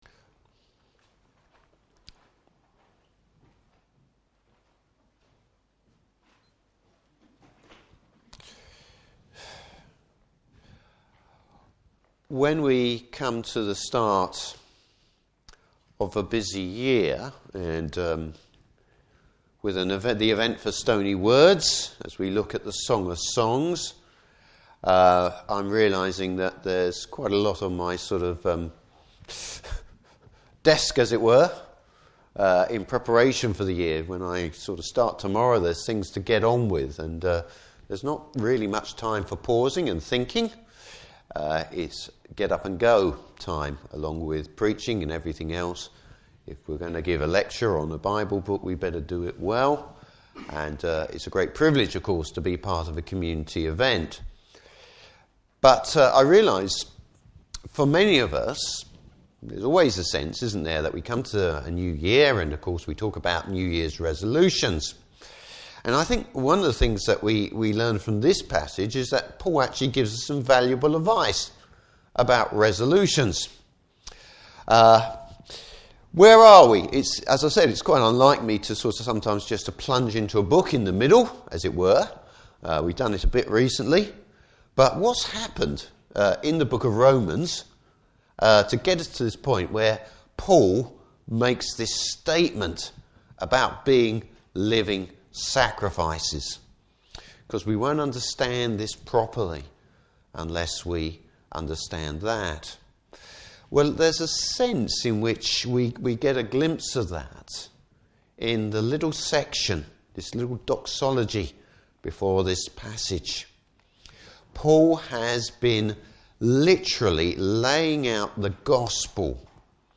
Service Type: Morning Service Bible Text: Romans 12:1-8.